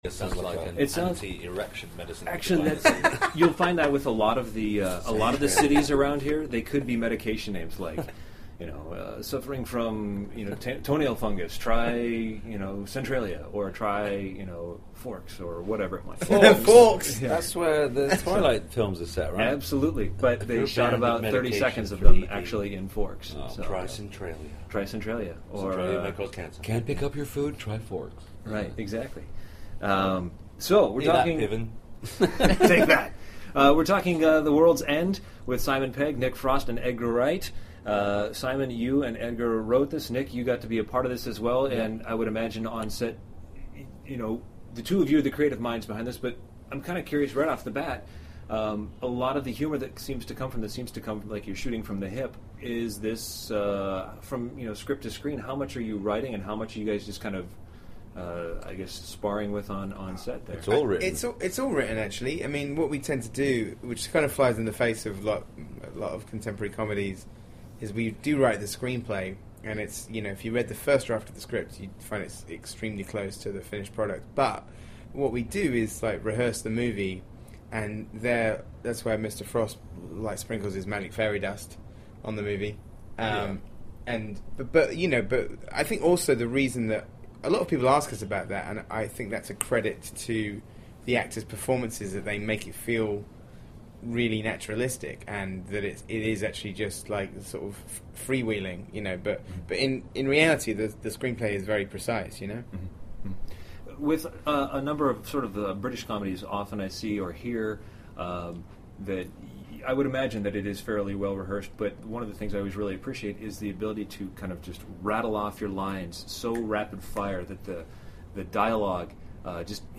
Incidentally if you’d like to hear my entire interview with Edgar, Simon and Nick, well….here’s a treat for you. They really are a lot of fun to talk with: The World’s End Full